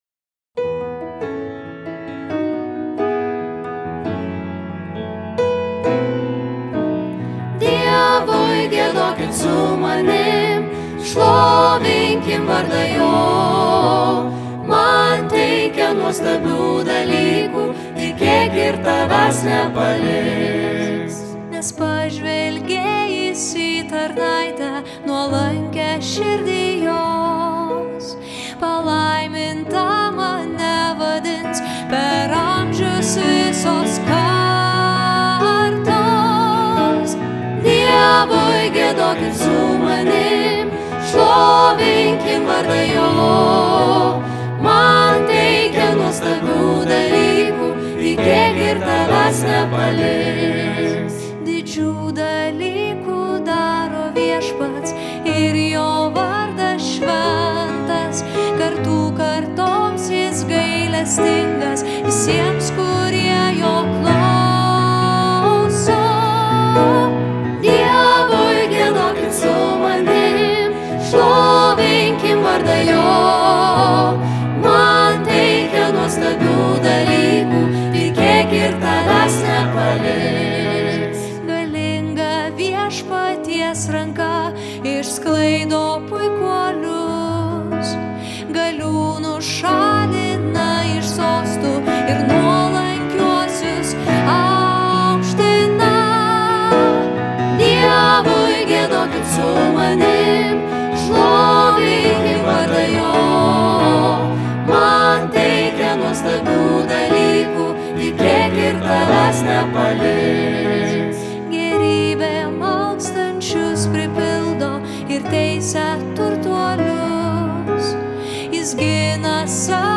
Choras: